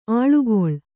♪ āḷugoḷ